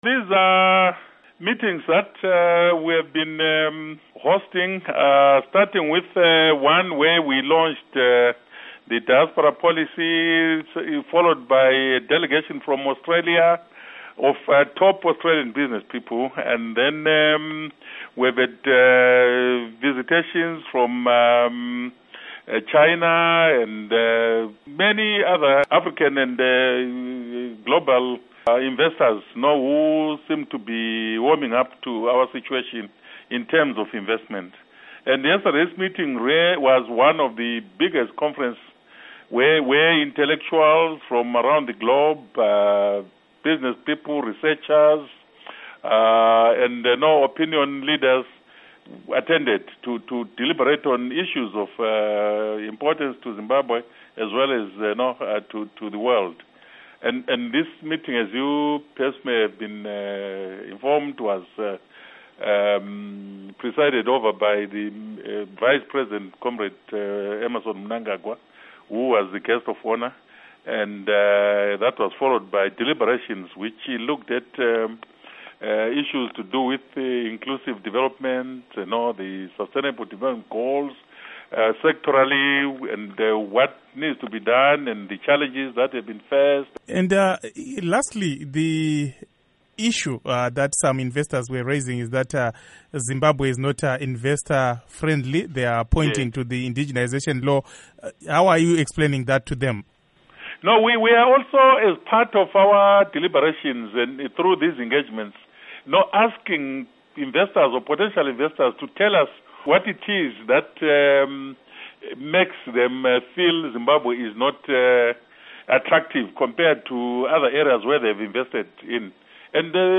Interview With Obert Mpofu